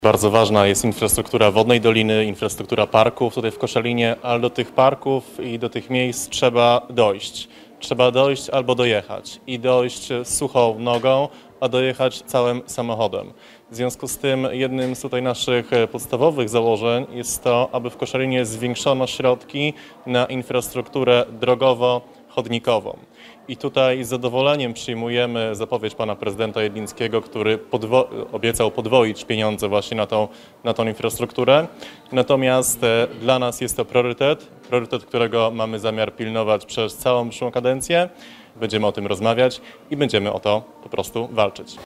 Wizje, plany i zamierzenia zostały przedstawione na poniedziałkowej konferencji prasowej.